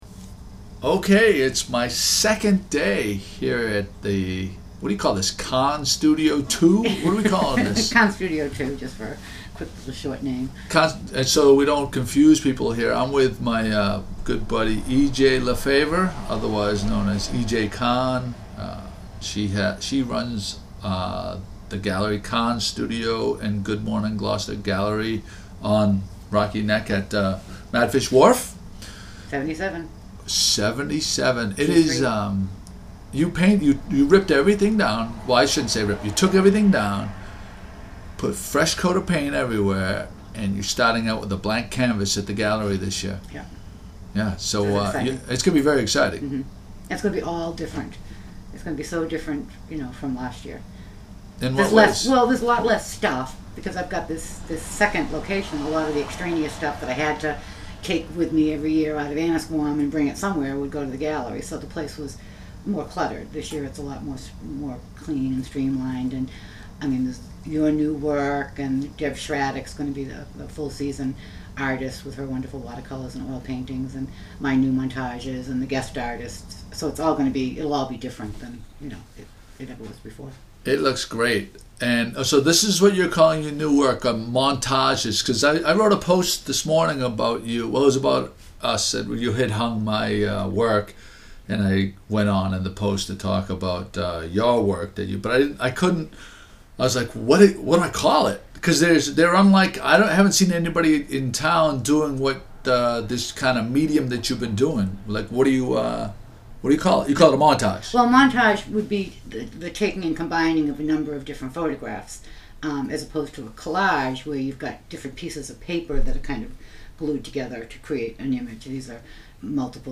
Smoker Laugh In The Background